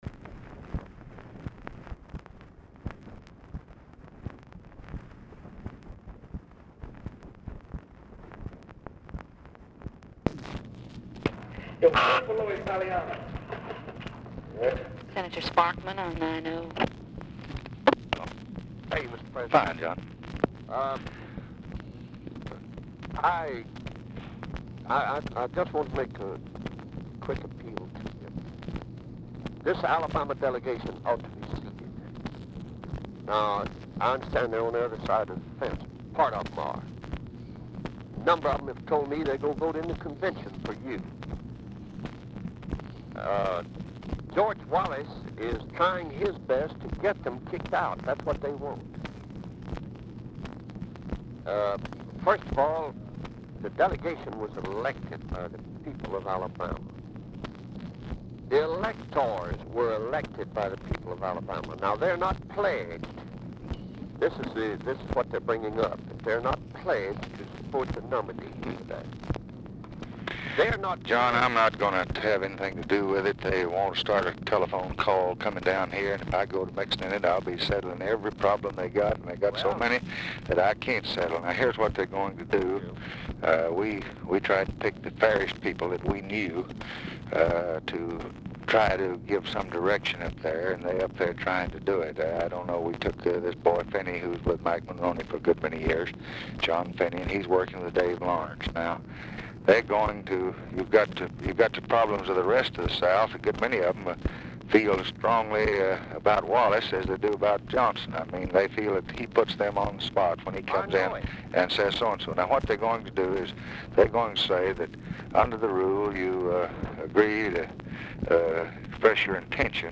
Telephone conversation # 5120, sound recording, LBJ and JOHN SPARKMAN, 8/22/1964, 12:00PM
POOR SOUND QUALITY DUE TO GREASE PENCIL WRITING ON ORIGINAL DICTABELT
Dictation belt
Oval Office or unknown location